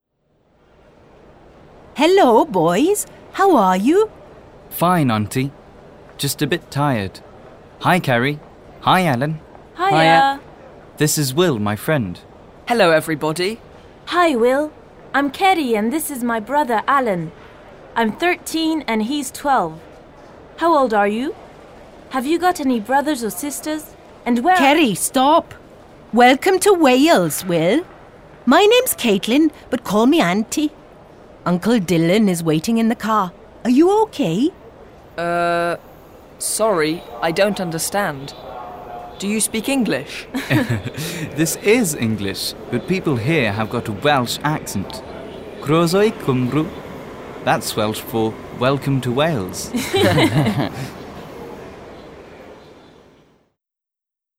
happy / excited / surprised / angry / sad
voix joyeuse, voix surprise, voix triste